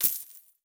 coin_med.wav